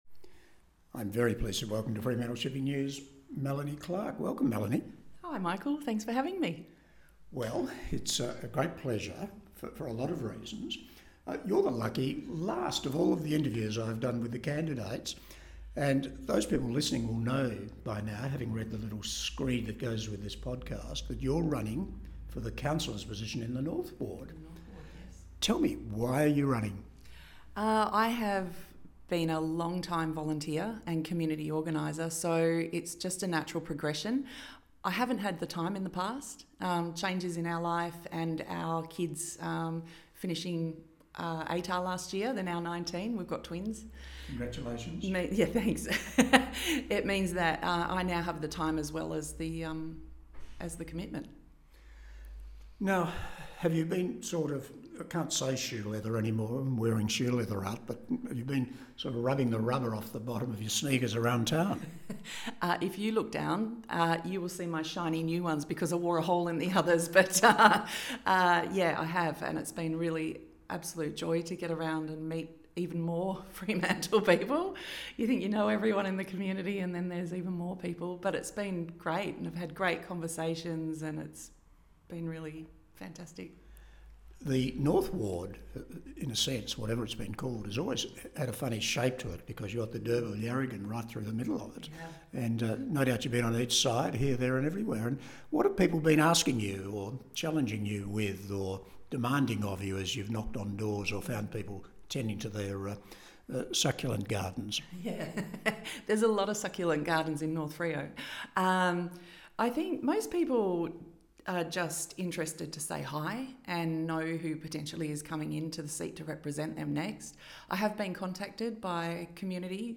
The North Ward Candidates Interviews
We have invited all candidates to be interviewed by our Editor in a getting-to -know-you podcast format explaining why they are running.